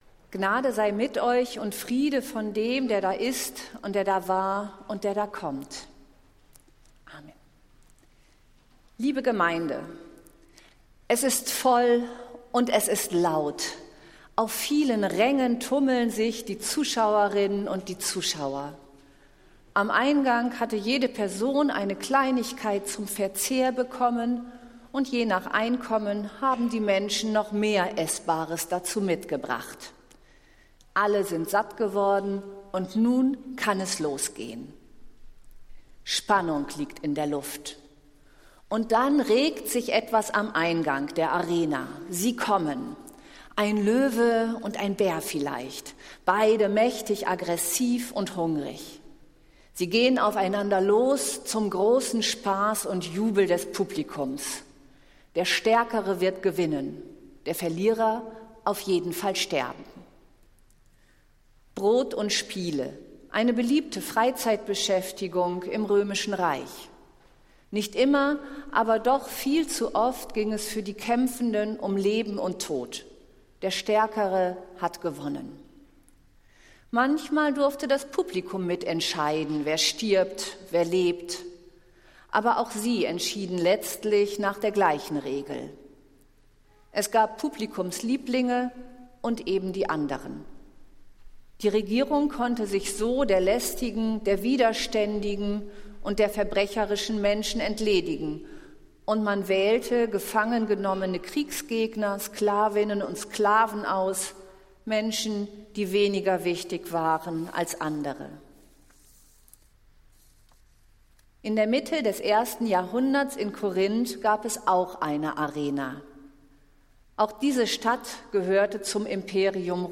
Predigt des Jahresgottesdienstes der Diakonischen Gemeinschaft Nazareth aus der Zionskirche, am Sonntag, den 7.Januar 2024